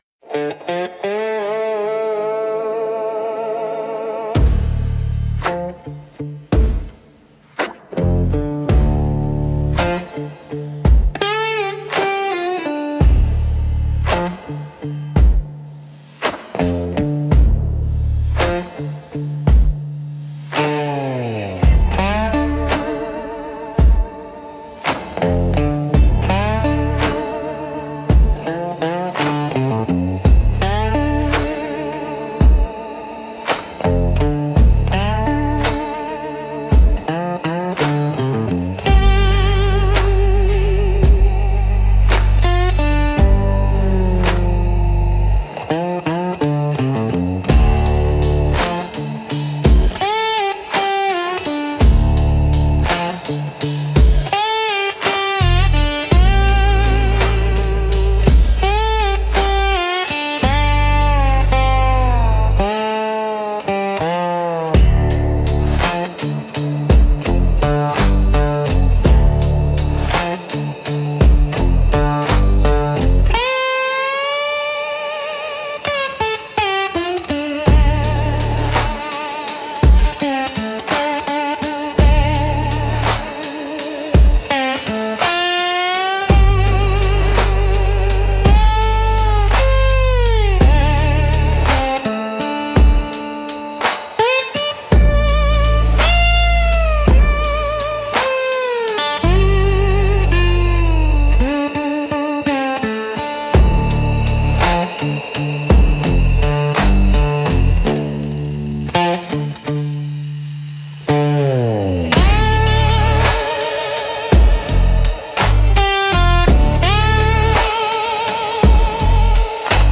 Instrumental - Holler from the Hollow 2.24